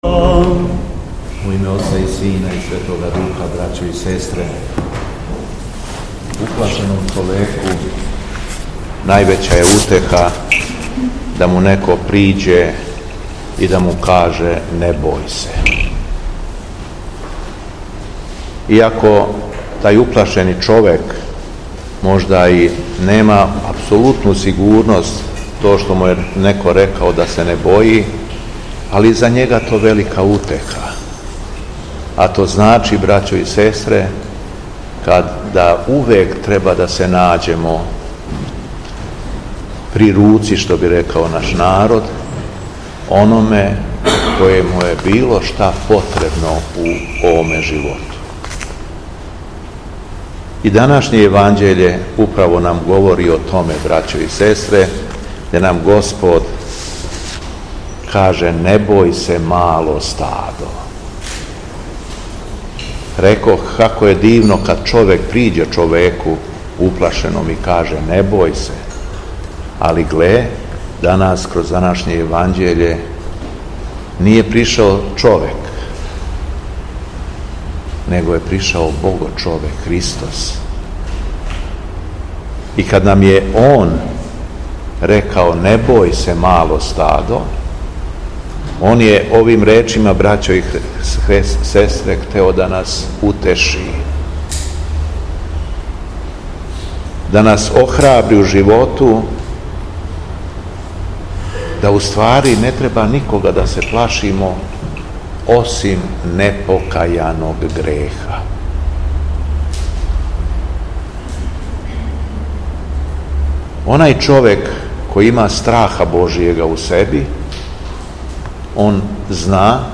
У пети четвртак по Духовима 10. јула 2025. године, Његово Високопресвештенство Митрополит шумадијски Г. Јован служио је Свету Литургију у Старој Цркви у Крагујевцу уз саслужење братства овога светога храма.
Беседа Његовог Високопреосвештенства Митрополита шумадијског г. Јована
Беседом се верном народу обратио Високопреосвећени Митрополит Јован рекавши да: